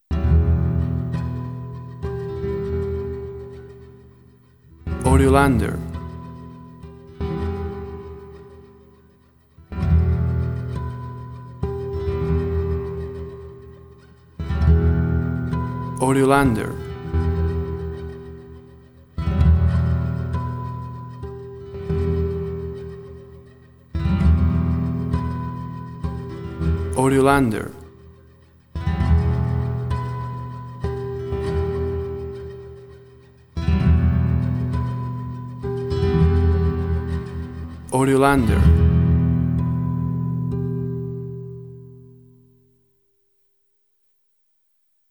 Ambient guitar sounds, big and distant in a gentle loop.
Tempo (BPM): 99